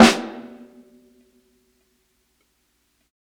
60s_SNARE_LOUD.wav